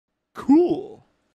Meme sound